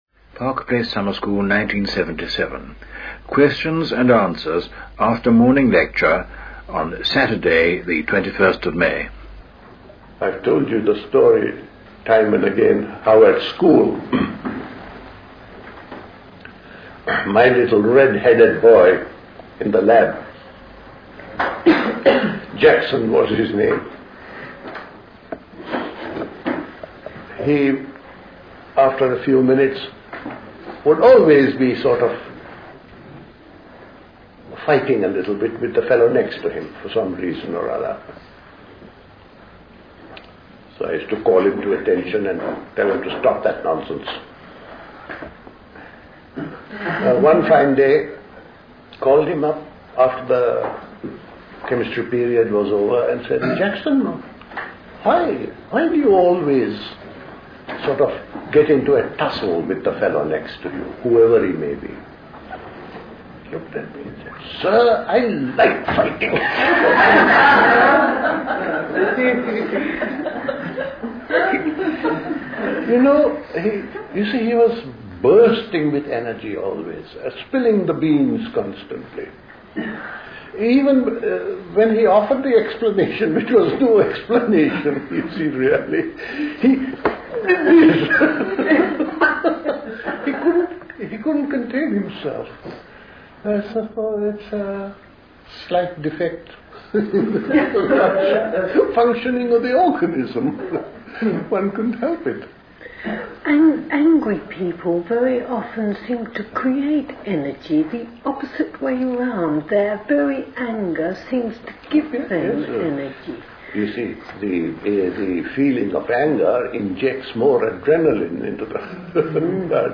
The Park Place Summer School Talks